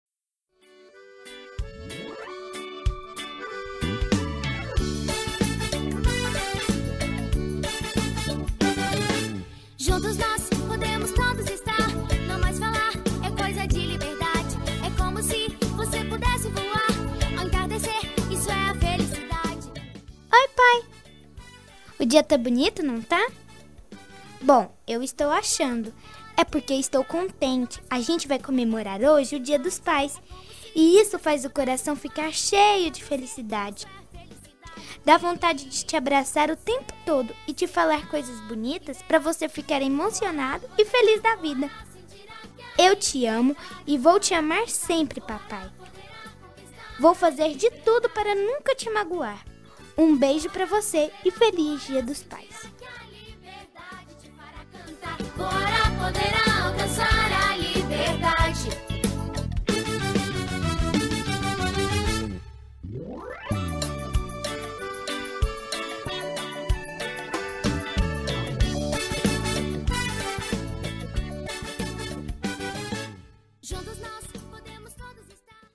Voz de Criança